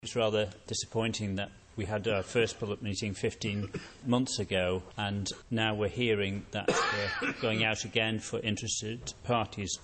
Interested parties will get more information in the New Year - however MLC David Anderson says the process is taking too long: